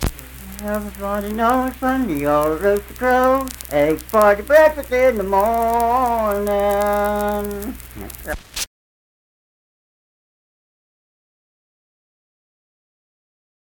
Unaccompanied vocal music performance
Verse-refrain 1(2).
Voice (sung)